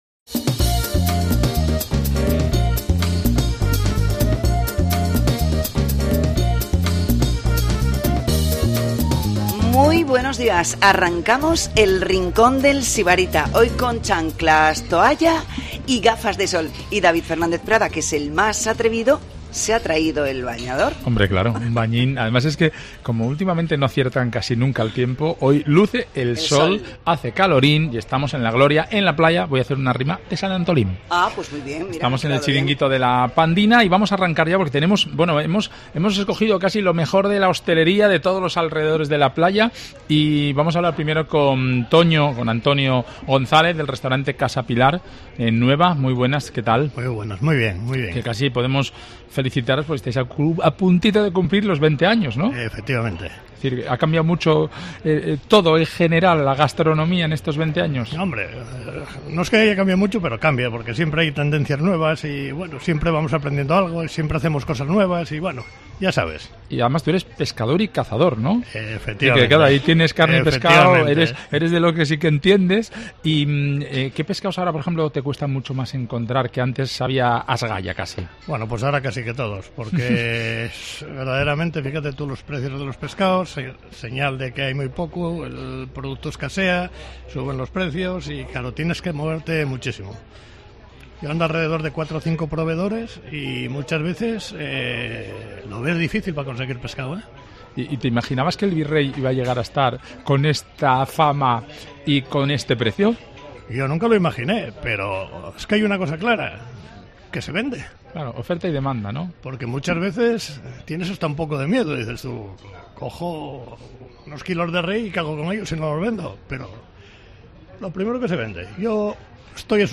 El Rincón del Sibartia ha viajado, este jueves, a Llanes para emitir un programa especial.